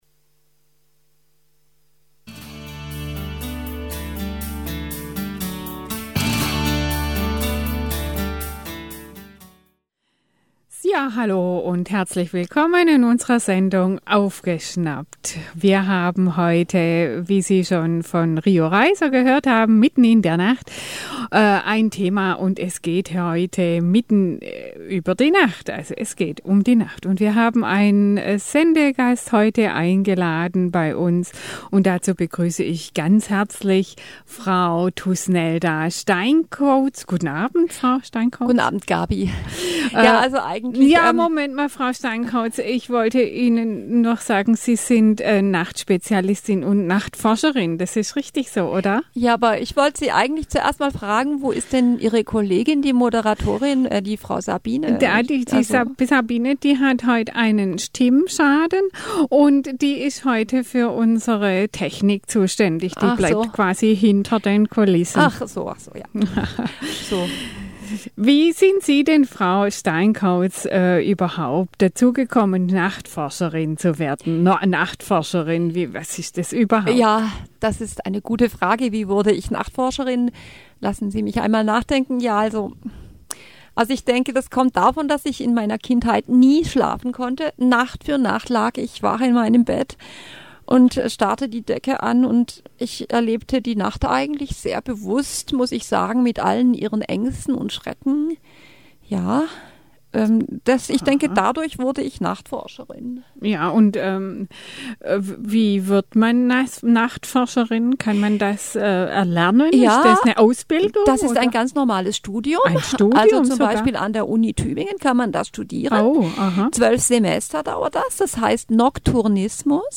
Nachtforscherin im Interview